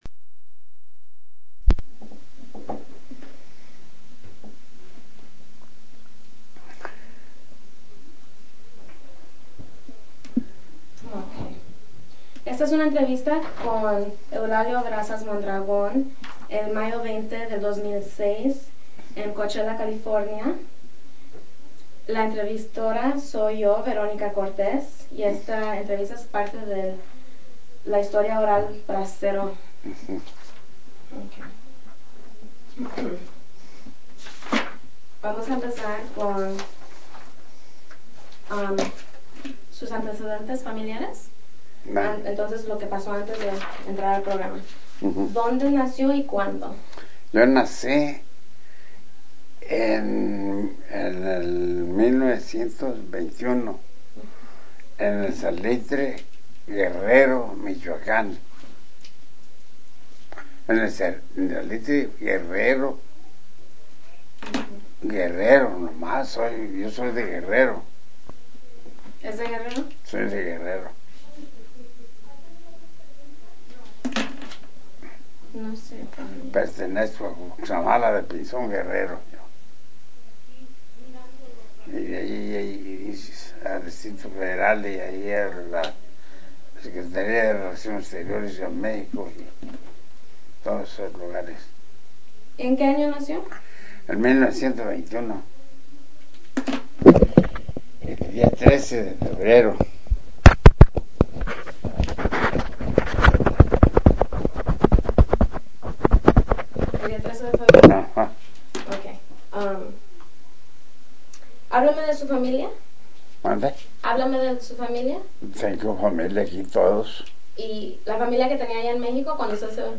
Summary of Interview